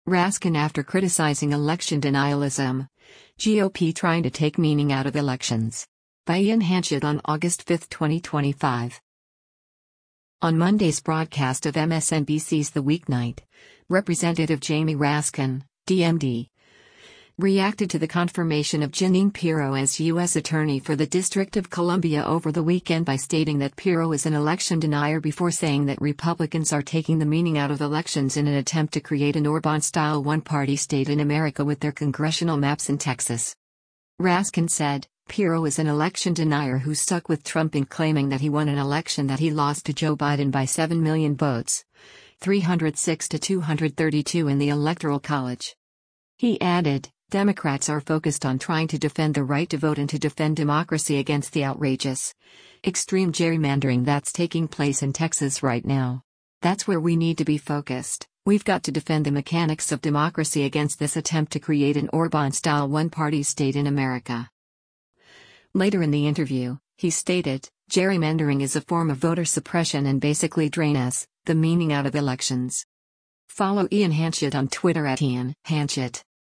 On Monday’s broadcast of MSNBC’s “The Weeknight,” Rep. Jamie Raskin (D-MD) reacted to the confirmation of Jeanine Pirro as U.S. Attorney for the District of Columbia over the weekend by stating that Pirro “is an election denier” before saying that Republicans are taking “the meaning out of elections” in an “attempt to create an Orbán-style one-party state in America” with their congressional maps in Texas.
Later in the interview, he stated, “Gerrymandering is a form of voter suppression and basically drain[s] the meaning out of elections.”